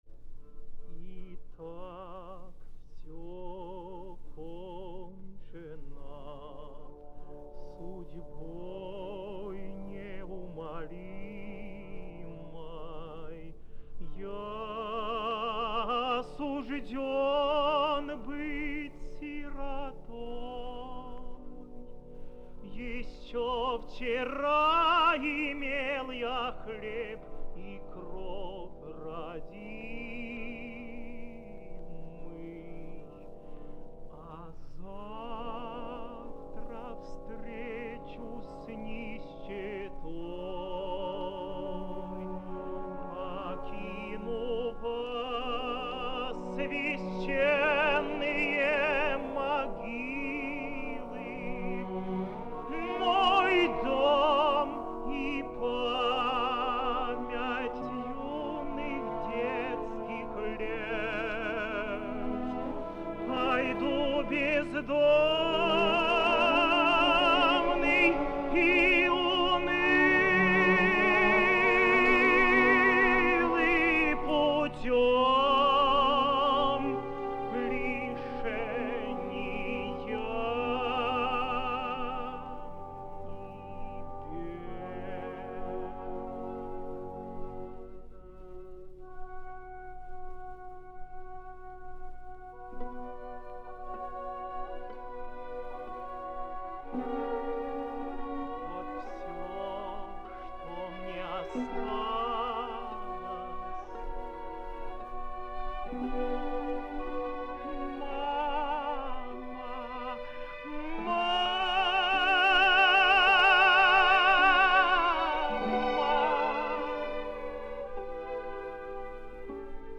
ivan-kozlovskiy---rechitativ-i-romans-dubrovskogo-(e.f.napravnik.-dubrovskiy)-(1955).mp3